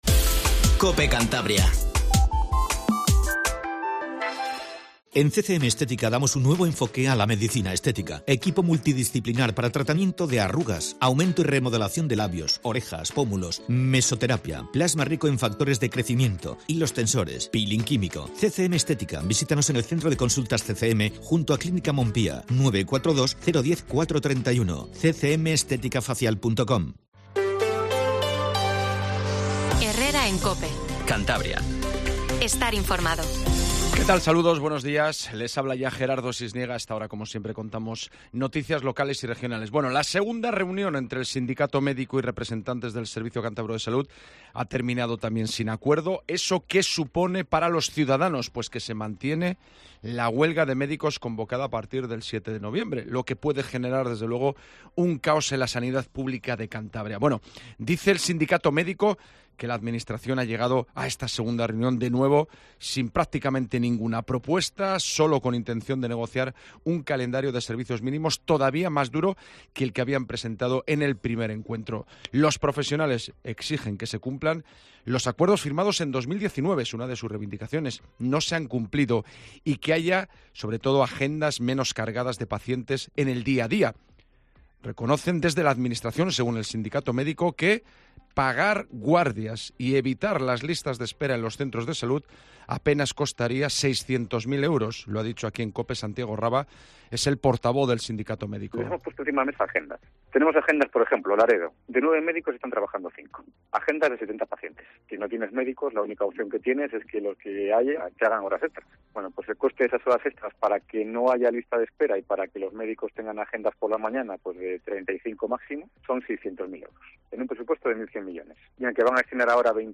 Informativo Matinal Cope